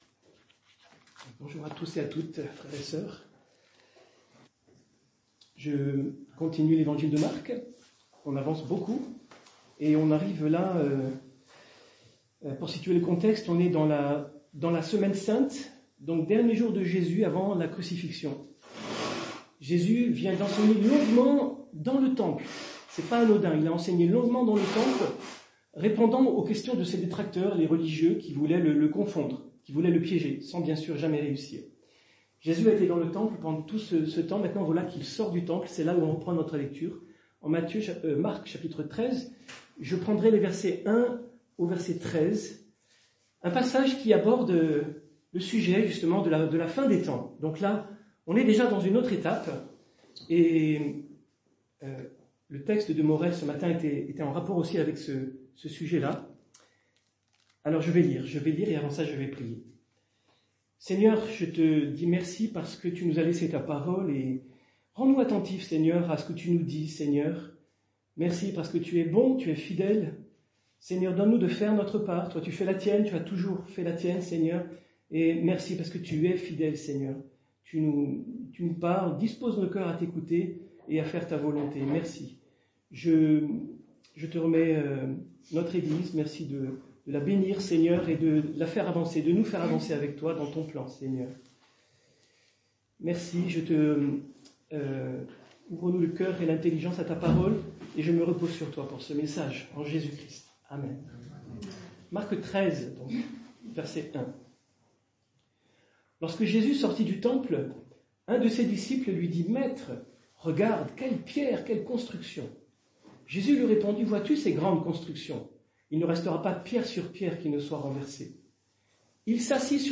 Culte du dimanche 8 décembre 2024 - EPEF